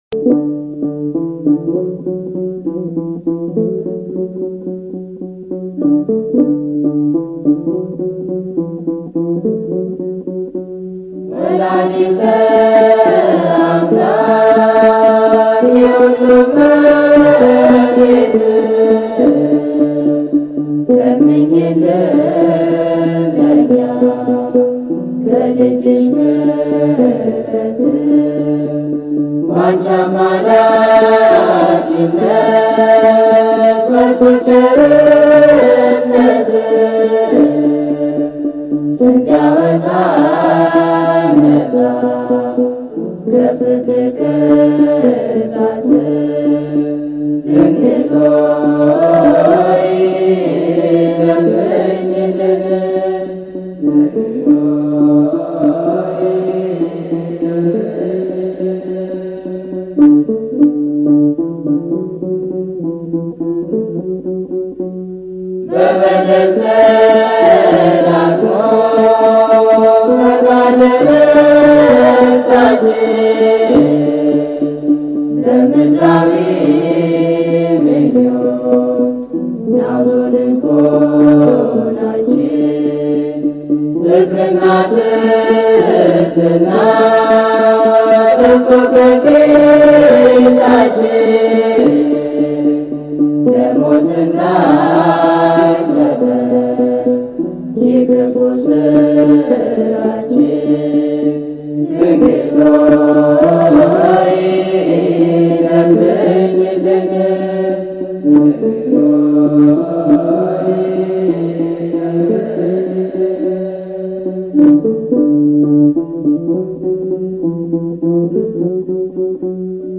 Choir (መዝሙር ክፍል)
Re'ese Adbarat Tserha Aryam Kidist Selassie Cathedral Ethiopian Orthodox Tewahedo Church --- Minneapolis, Minnesota